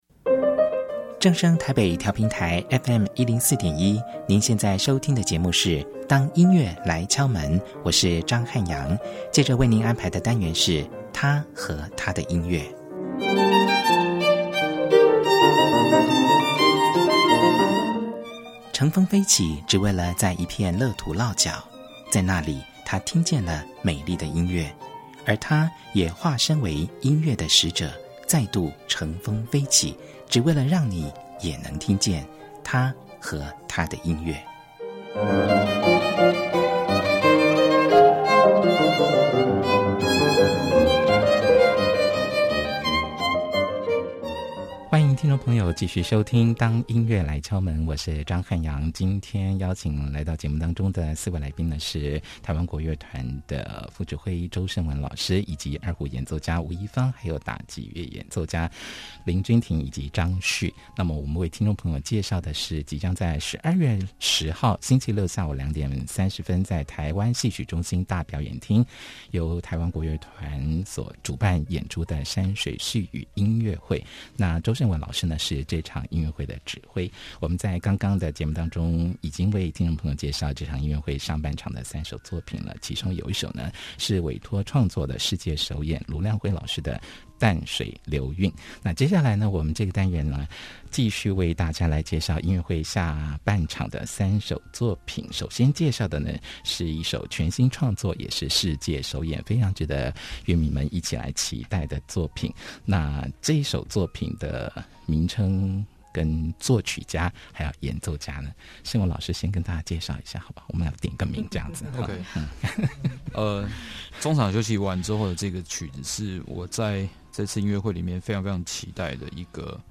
在本集節目中，無論是談到疫情期間的自處之道，或是對於音樂會曲目的樂思解讀，四位來賓都有非常精彩的分享與對話，笑語不斷，歡迎收聽。